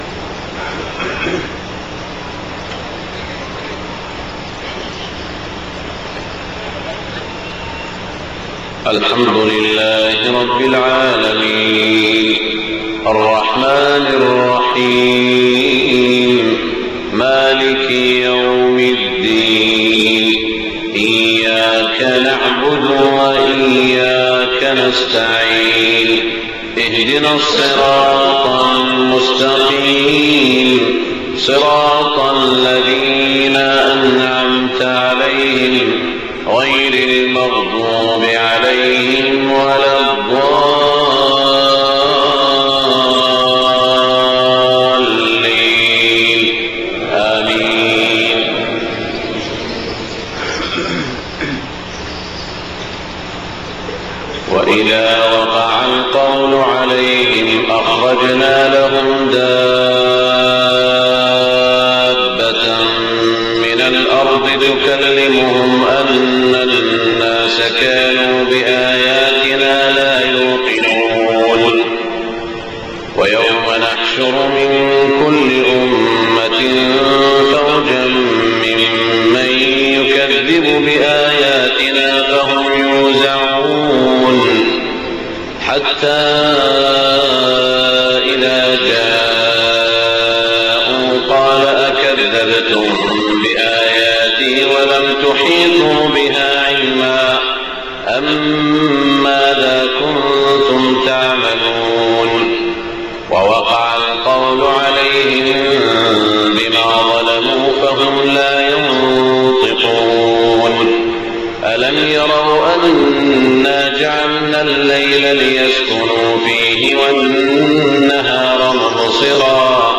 صلاة الفجر 9-4-1428هـ من سورتي النمل و الشورى > 1428 🕋 > الفروض - تلاوات الحرمين